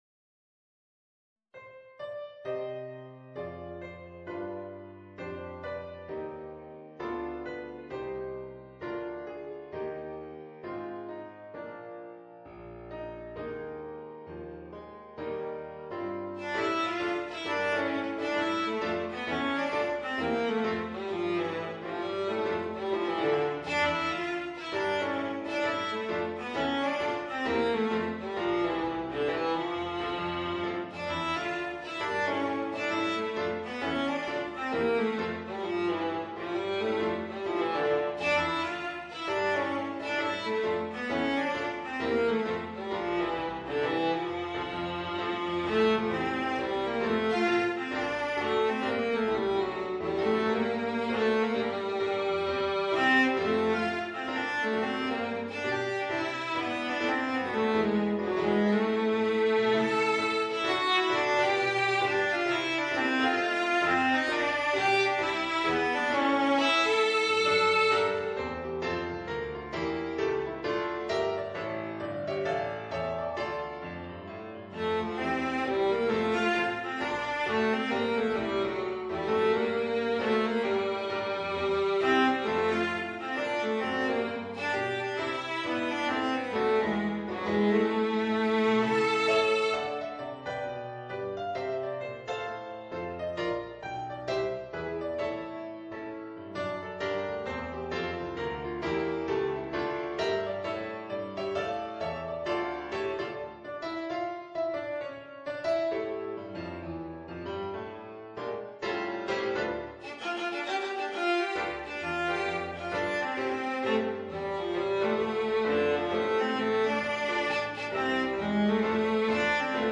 Voicing: Viola and Piano